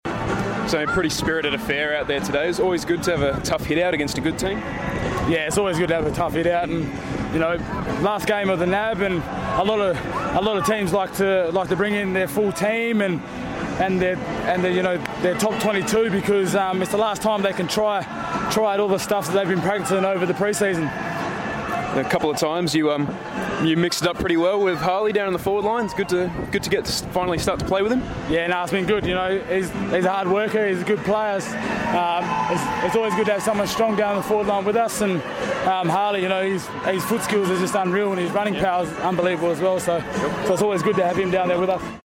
Michael Walters spoke on ground following the win against the Cats